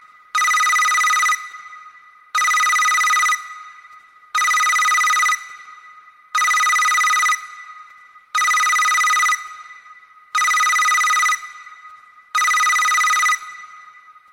iphone-simple_24818.mp3